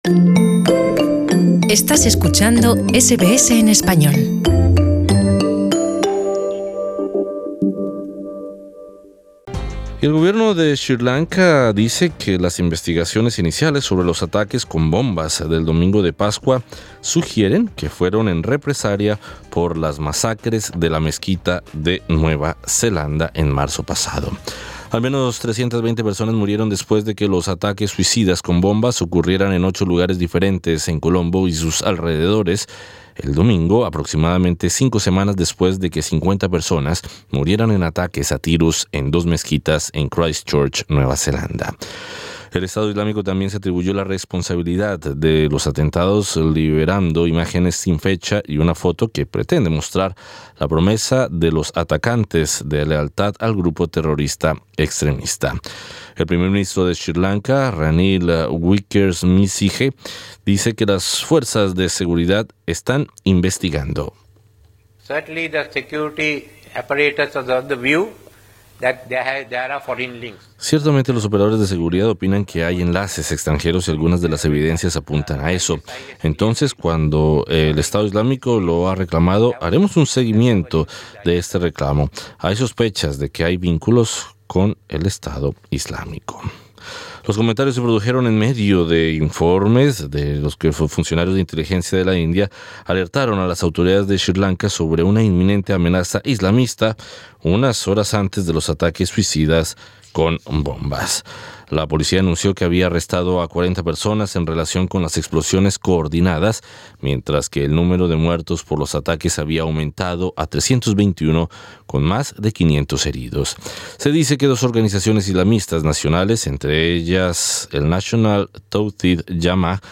conversamos con el académico y experto en estrategia y seguridad en Canberra